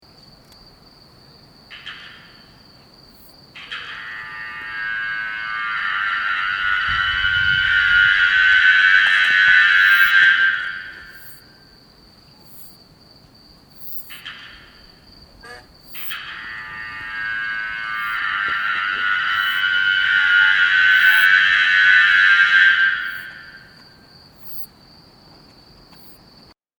Flying gooseberries produce sound in a way similar to other grasshoppers, by rubbing their hind legs against a row of pegs on their abdomen (to be exact, most grasshoppers rub their legs against a modified vein on their wings, but the principle is the same.)
This allows him to broadcast his song at distances that have no parallels among insects – a single male can be heard from over a mile away.